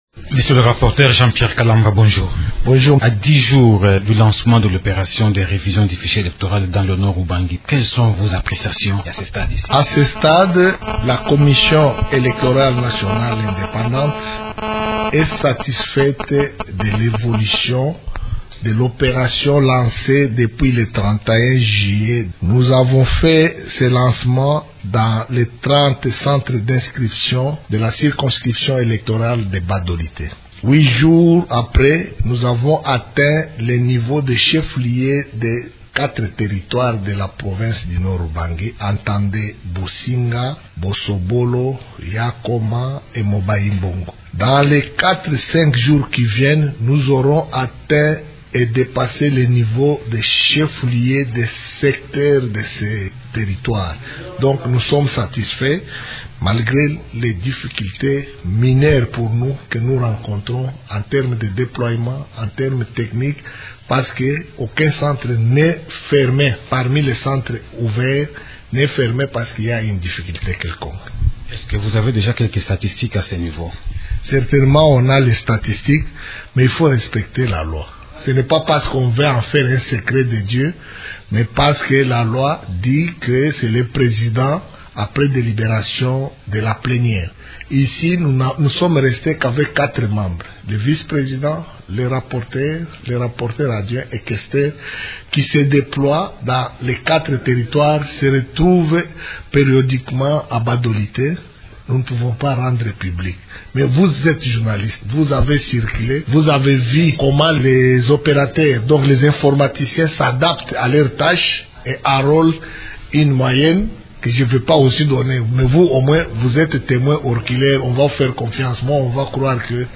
Une dizaine de jours après le lancement de l’opération de révision du fichier électoral au Nord-Ubangi, le rapporteur de la Commission électorale nationale indépendante (CENI), Jean-Pierre Kalamba, fait le point sur le déroulement de cette opération. Il est l’invité de la rédaction de Radio Okapi.